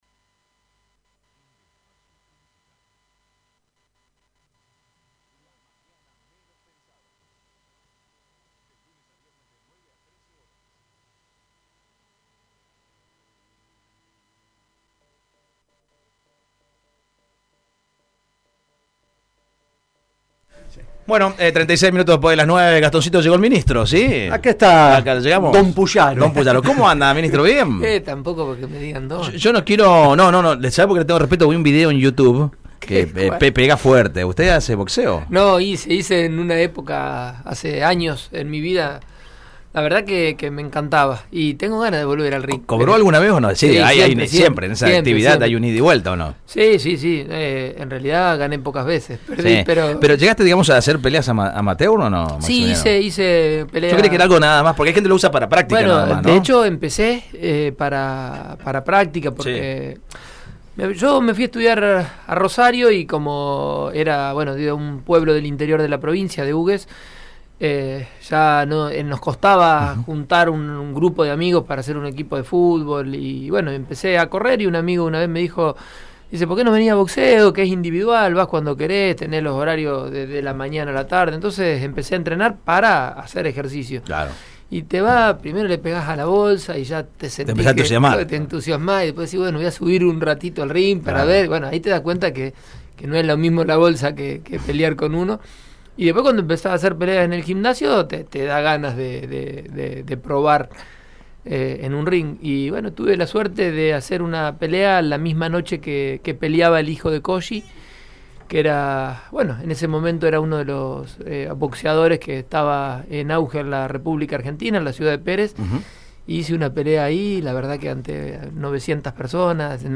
El ministro de Seguridad, Maximiliano Pullaro estuvo en vivo en los estudios de RADIO EME en una entrevista exclusiva y mano a mano en «La Mañana Menos Pensada».